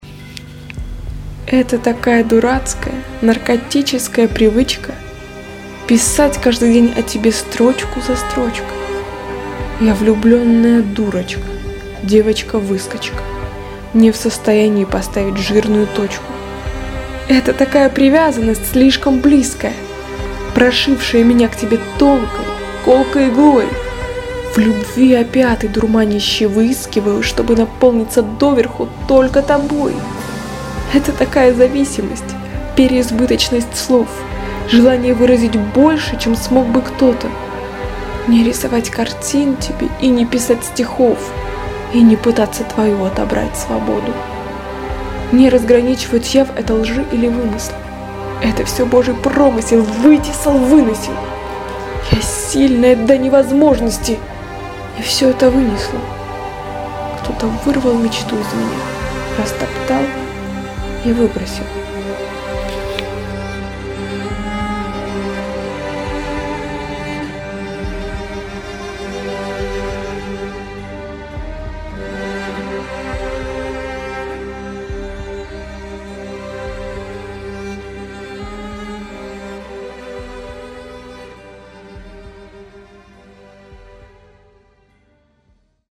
Нашла у себя на компьютере запись лучшего качества, подобрала музыку, вот только название хочется другое дать этому стихотворению.